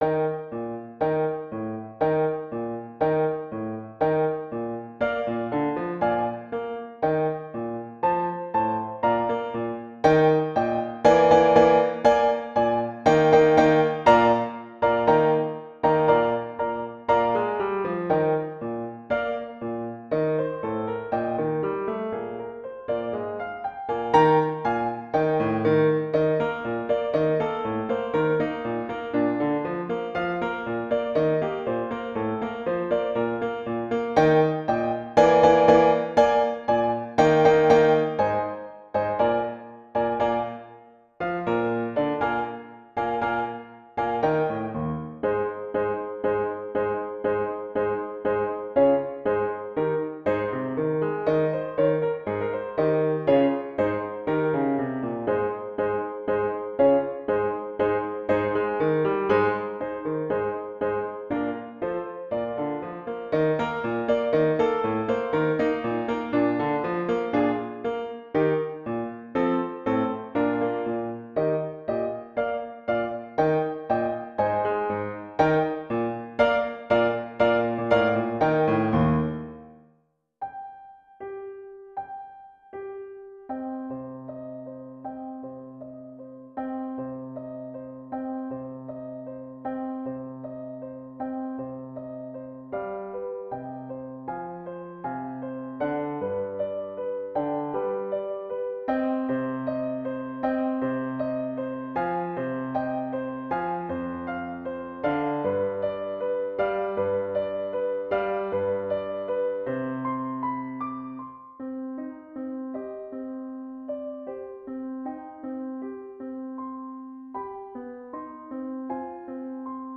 Audio: Piano part alone